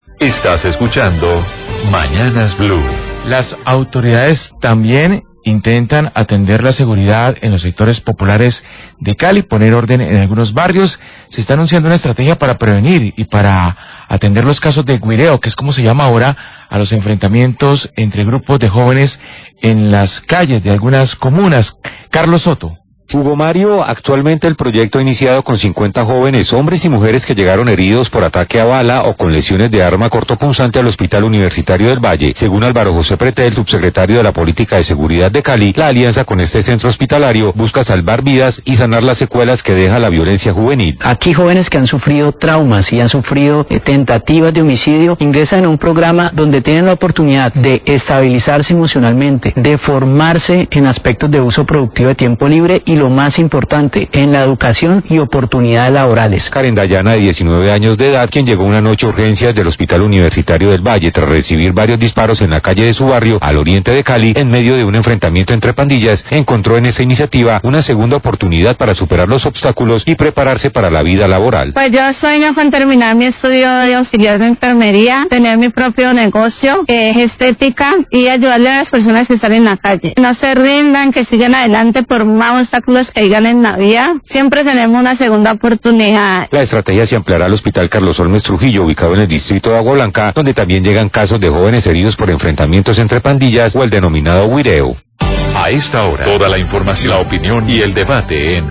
Radio
El Subsecretario de Política de Seguridad de Cali, Jorge Pretelt, habla del programa en alianza con el Hospital Universitario del Valle, para atender víctimas de violencia juvenil producto de los enfrentamientos entre pandillas denominados "guireo".